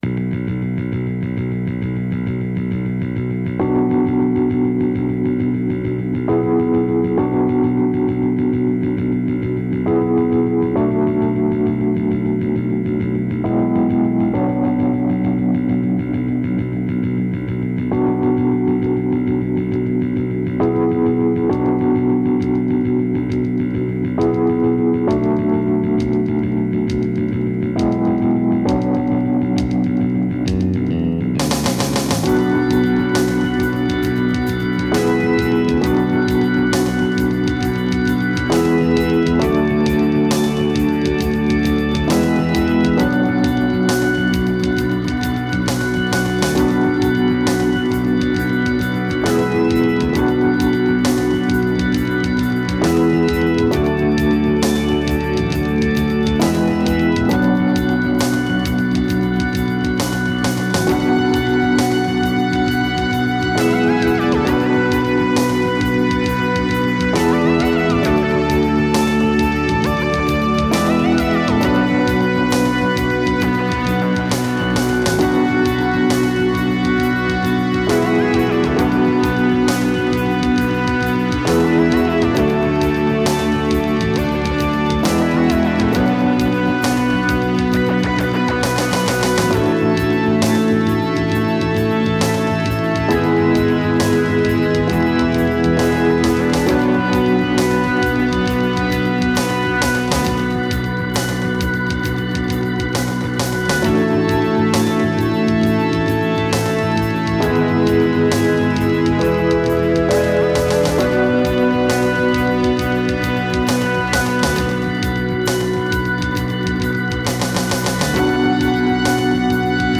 instrumental
Recorded AAD - Analogue Analogue Digital